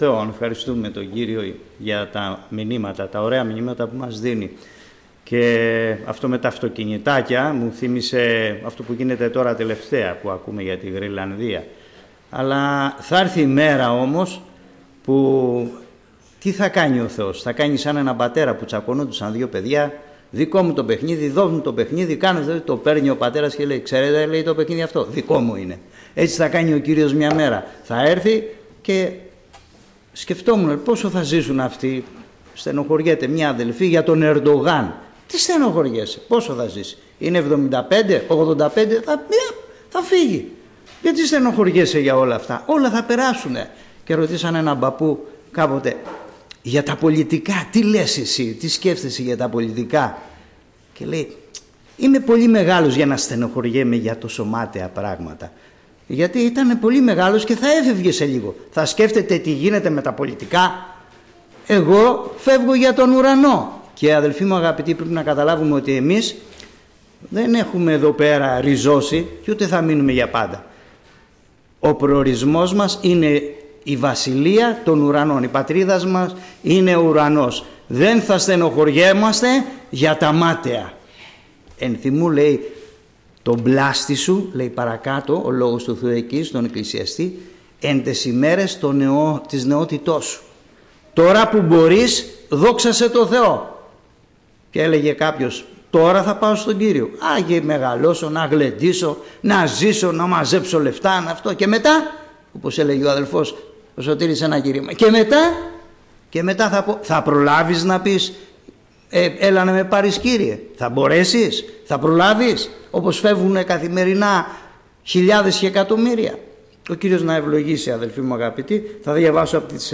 Κήρυγμα Παρασκεύης